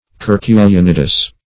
Search Result for " curculionidous" : The Collaborative International Dictionary of English v.0.48: Curculionidous \Cur`cu*li*on"i*dous\ (k?r`-k?-l?-?n"?-d?s), a. (Zool.)
curculionidous.mp3